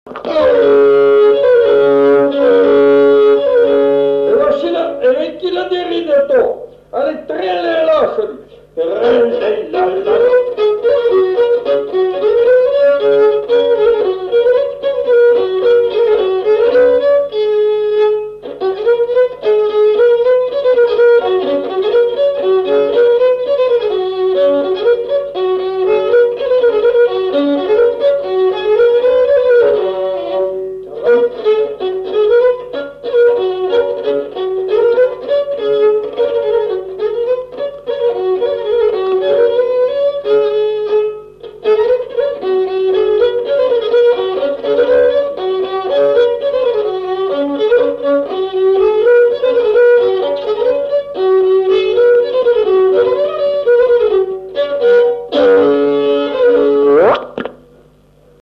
Scottish-valse